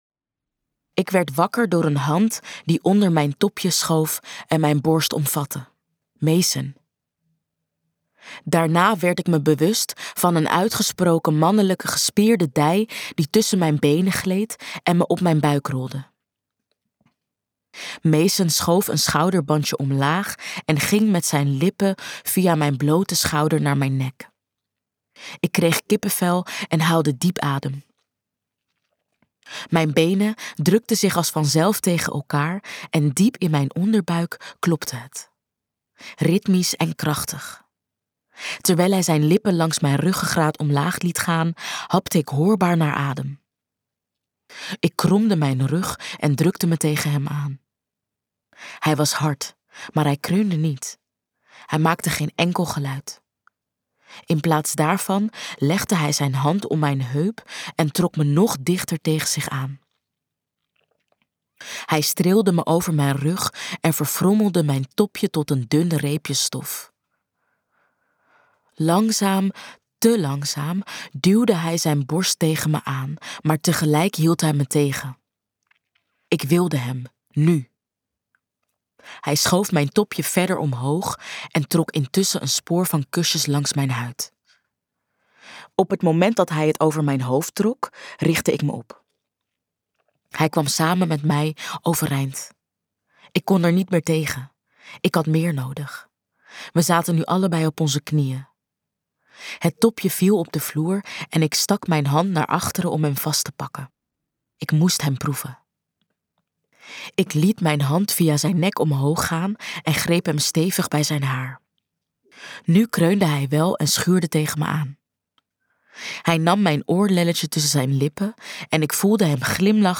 Luisterboek